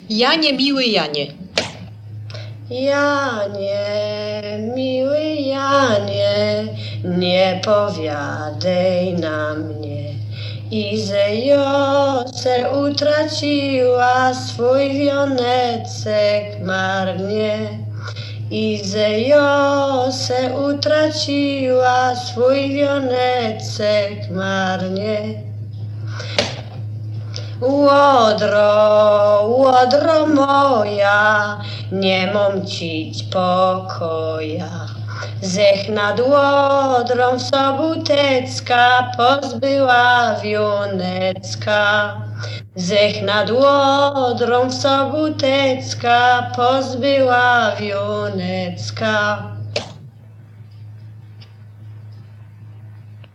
Śląskie, powiat tarnogórski, miasto Kalety